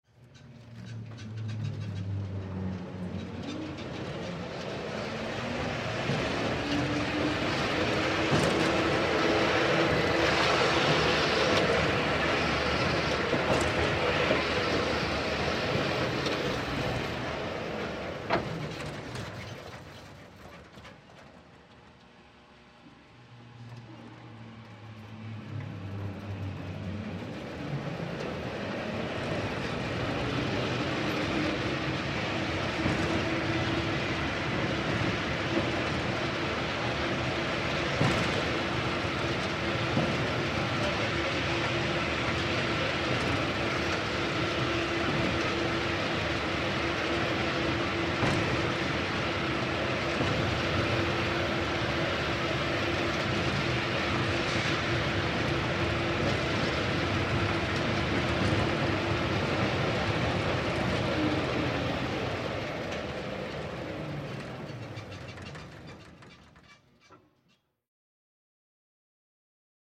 Звуки внутри башни поворотного крана стрелового типа со стрелой